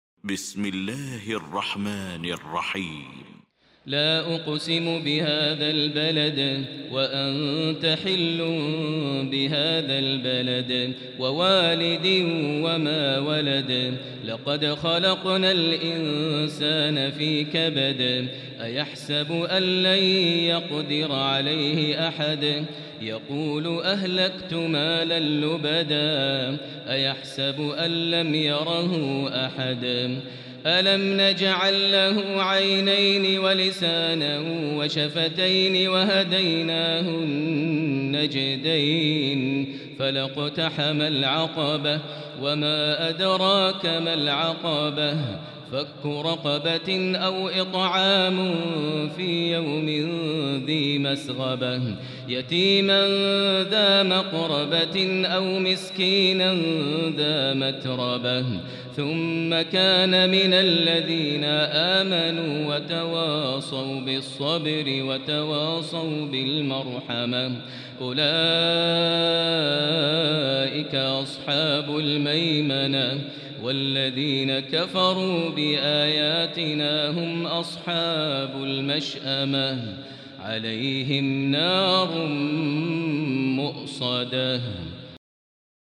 المكان: المسجد الحرام الشيخ: فضيلة الشيخ ماهر المعيقلي فضيلة الشيخ ماهر المعيقلي البلد